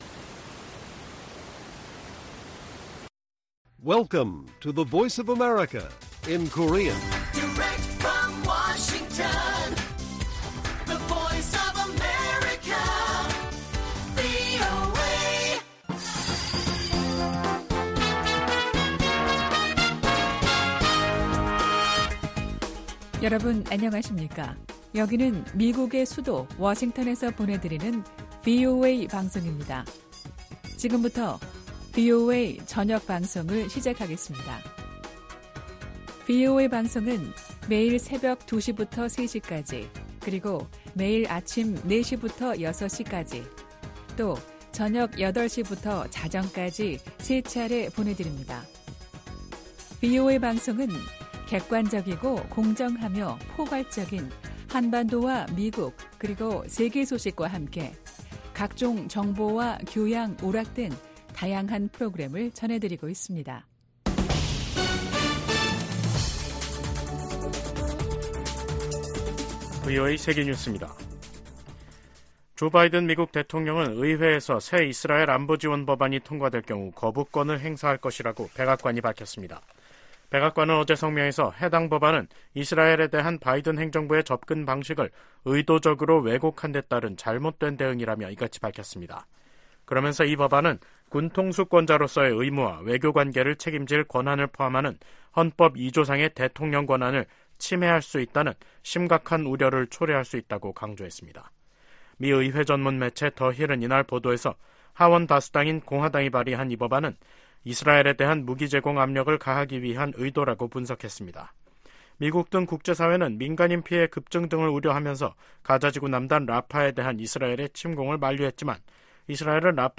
VOA 한국어 간판 뉴스 프로그램 '뉴스 투데이', 2024년 5월 15일 1부 방송입니다. 우크라이나를 방문 중인 토니 블링컨 미 국무장관이 14일 러시아의 침략 전쟁과 이를 지원하는 북한, 이란을 비판했습니다. 북한의 미사일 도발 가속화로 인해 안보 태세를 강화하는 것 외에는 다른 선택지가 없다고 국무부 동아태 담당 차관보가 말했습니다.